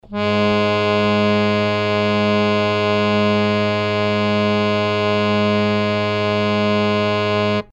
harmonium
Gs2.mp3